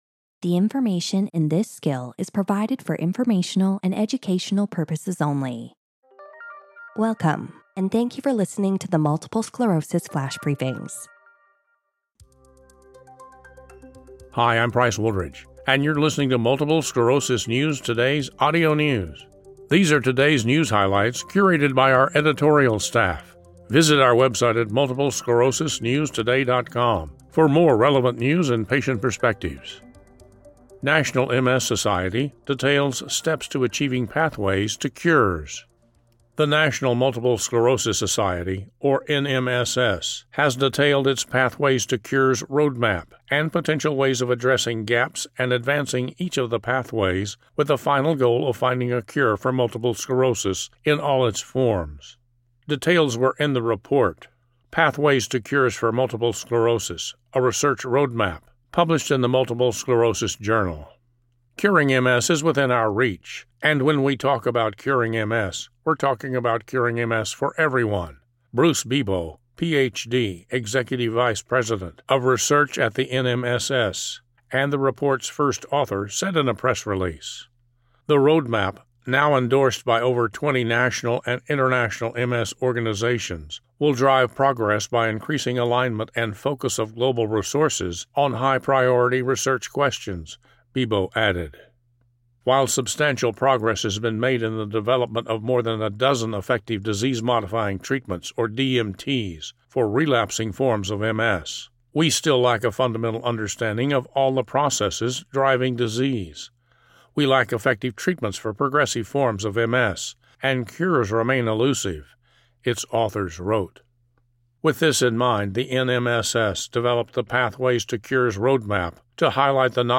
He also reads an article from MS News Today’s "Expert Voices"